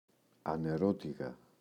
ανερώτηγα [ane’rotiγa]